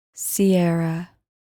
Pronounced: see-AIR-rah